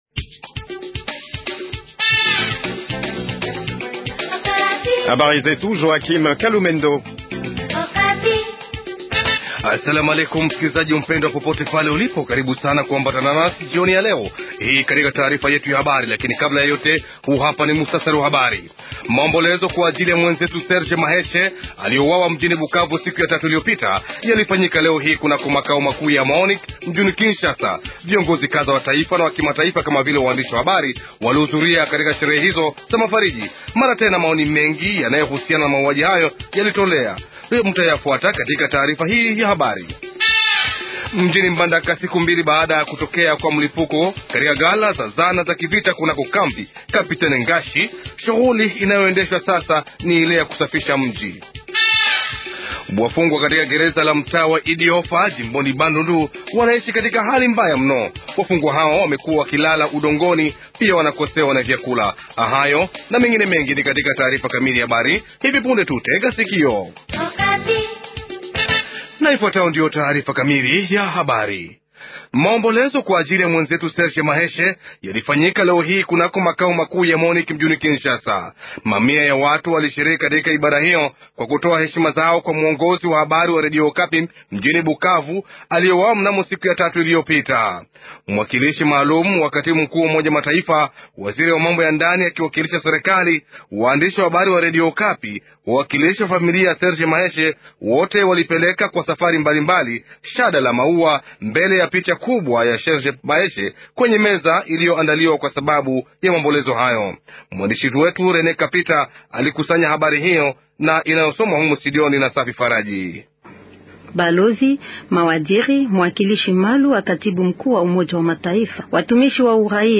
Titre 2 : Au lendemain de l’incendie qui a ravagé le dépôt de munitions des FARDC à Mbandaka, l’heure est au nettoyage de la ville. Reportage sur cette opération dans ce journal.